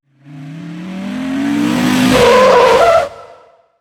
Frenazo de un coche